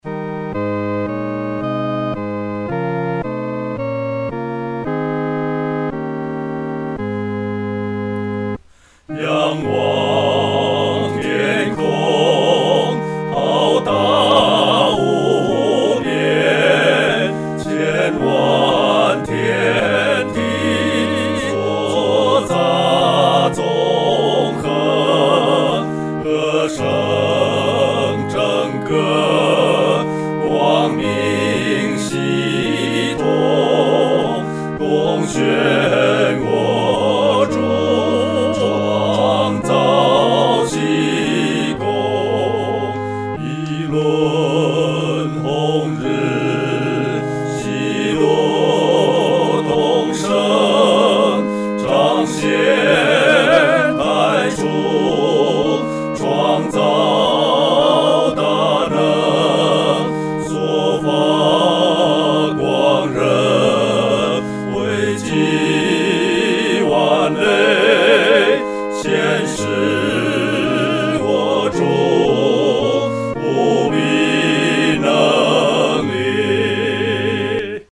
合唱（四声部）
创造奇功-合唱（四声部）.mp3